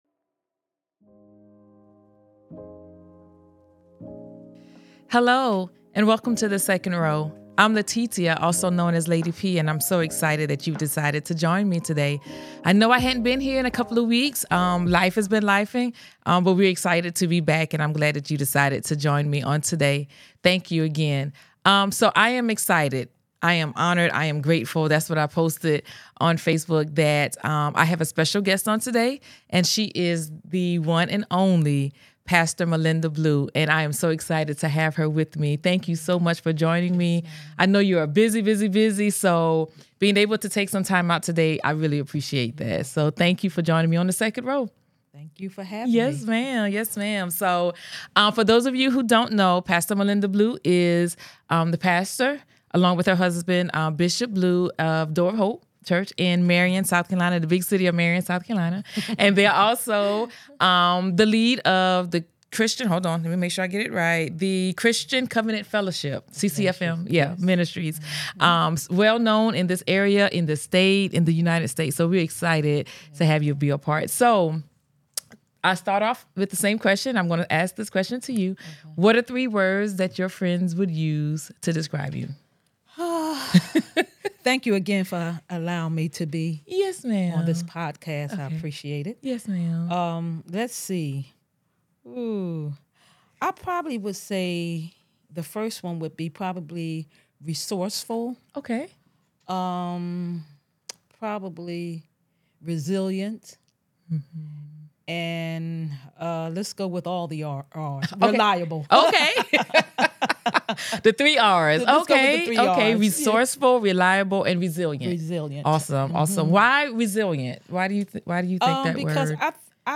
This enlightening conversation will inspire you to trust the process!